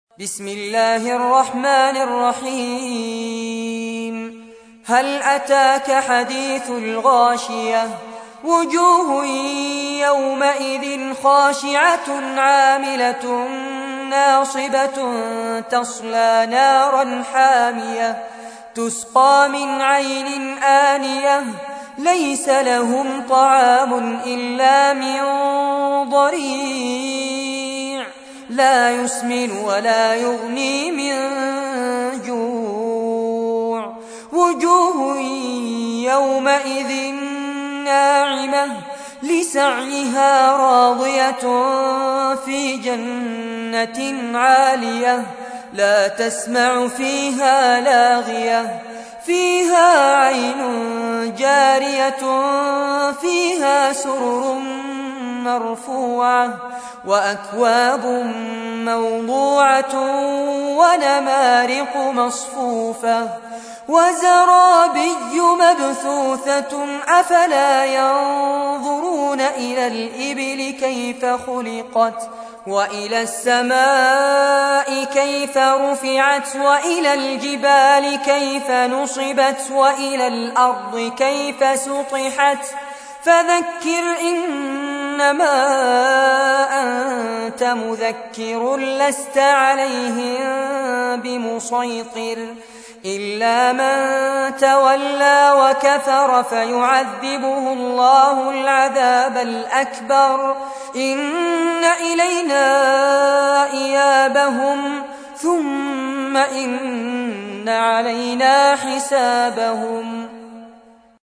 تحميل : 88. سورة الغاشية / القارئ فارس عباد / القرآن الكريم / موقع يا حسين